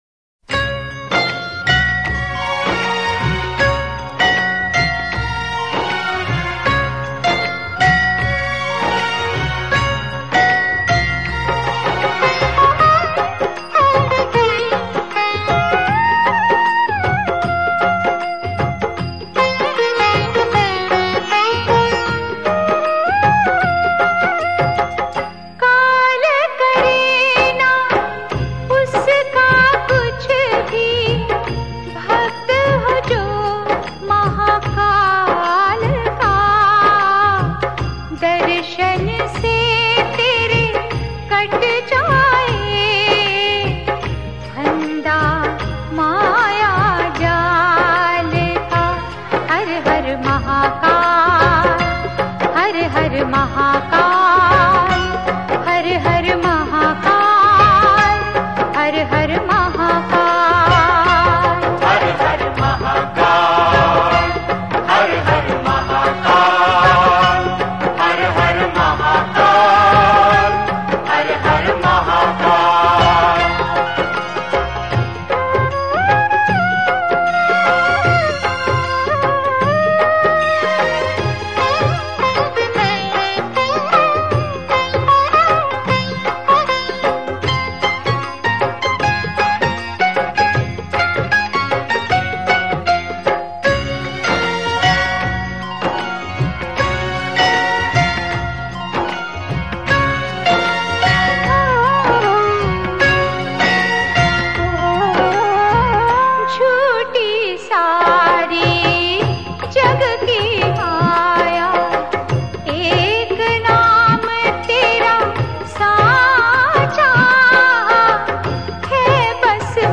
Shiv (Bholenath) Single Bhajan